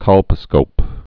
(kŏlpə-skōp)